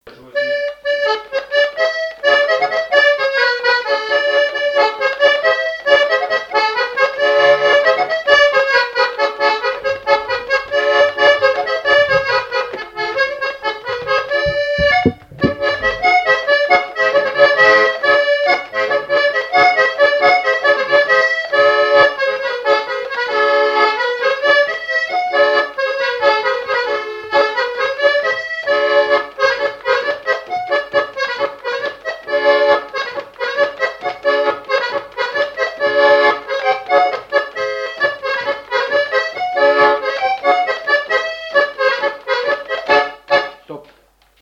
Couplets à danser
branle : courante, maraîchine
Répertoire et souvenir des musiciens locaux
Pièce musicale inédite